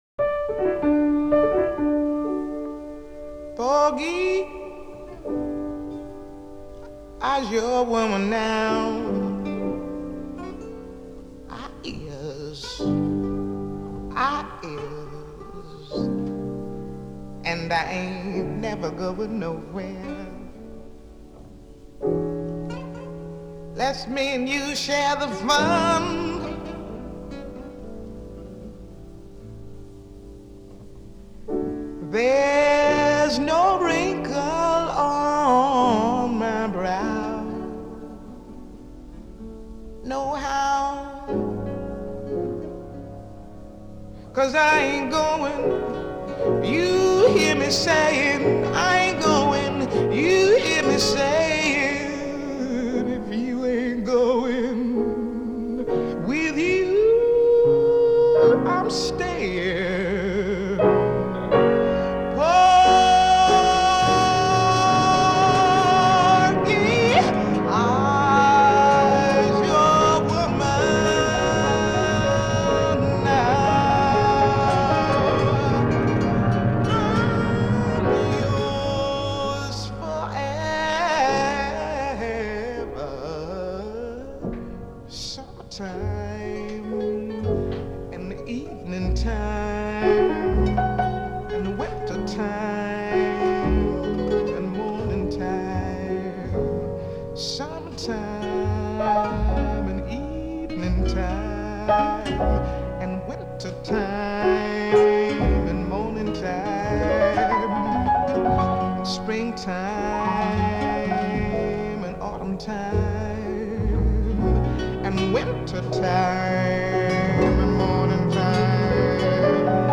Her delivery is so soulful.
Genre: Musical